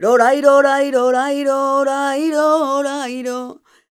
46b08voc-b.aif